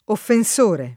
[ offen S1 re ]